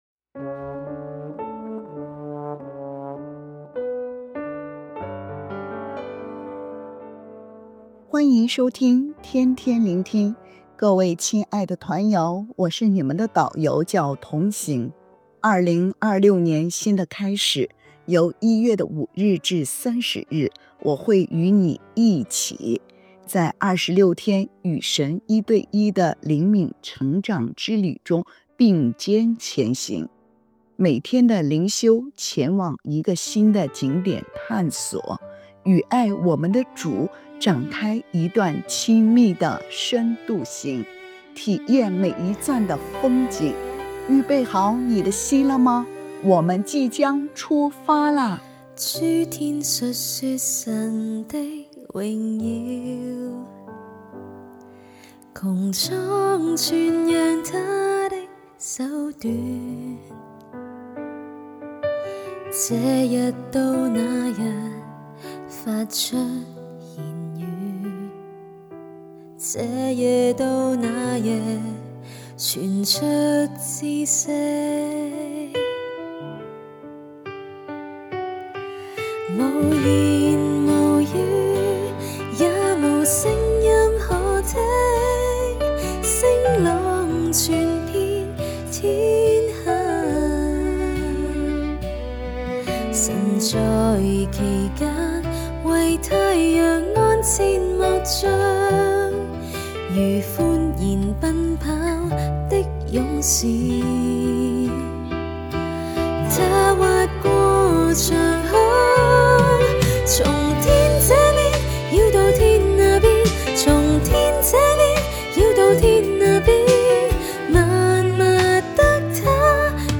🎶靈修詩歌：《詩篇19》